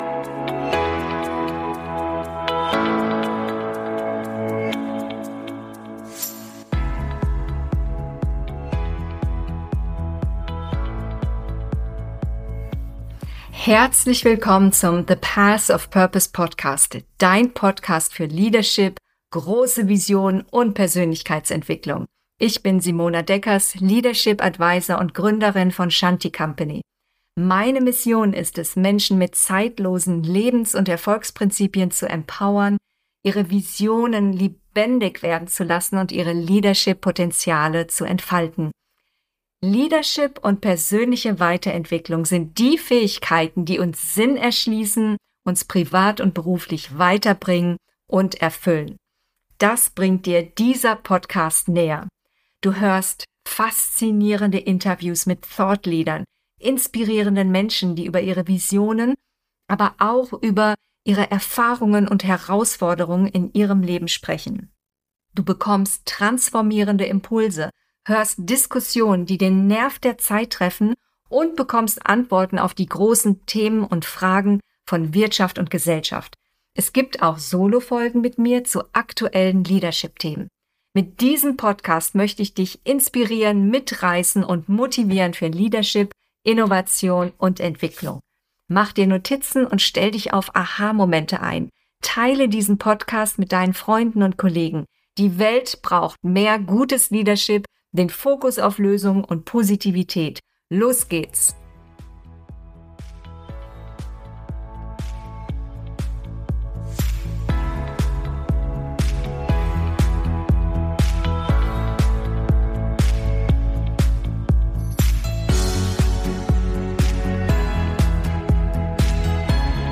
Social Impact: Wirtschaftlichen Erfolg mit sozialer Verantwortung verbinden - Interview